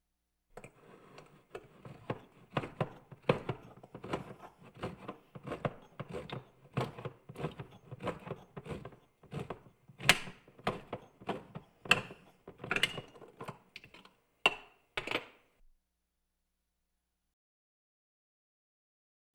Can Opener Manual Sound
household
Can Opener Manual